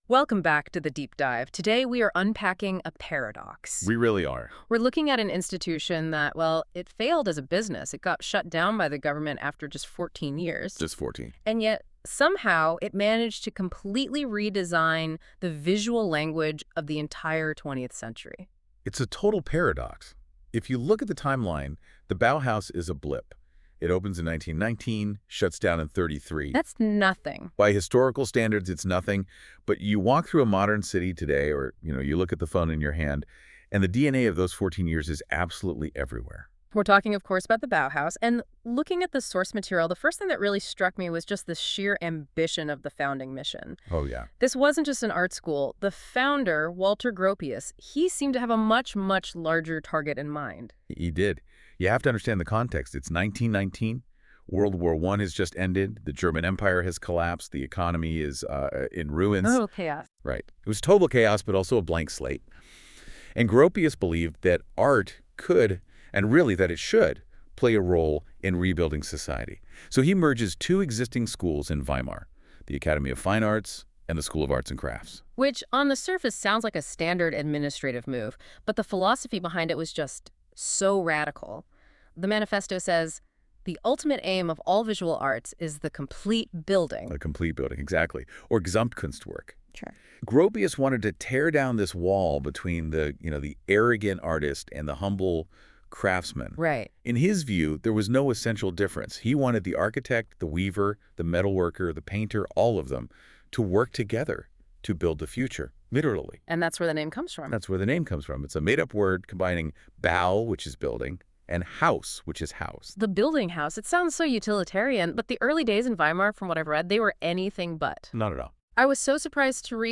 A discussion on Bauhaus 1919-1933 (created by NotebookLM from my notes):